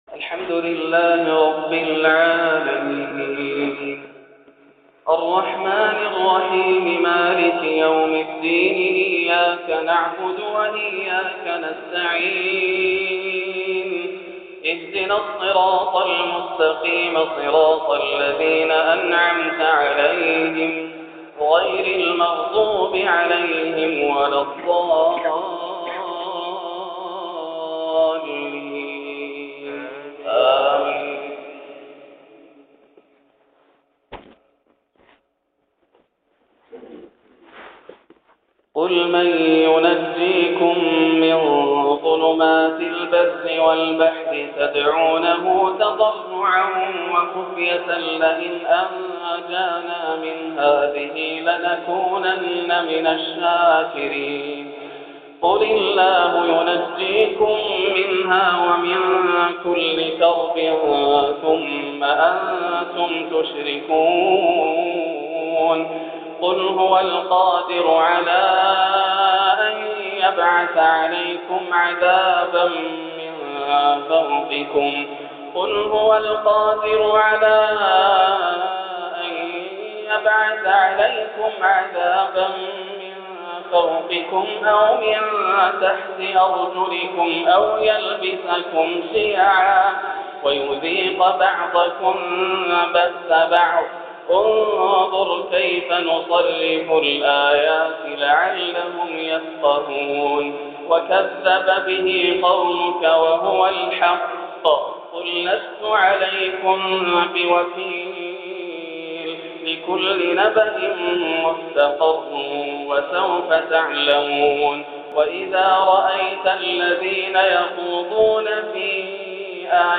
تلاوة من سورة الأنعام 1430 > عام 1430 > الفروض - تلاوات ياسر الدوسري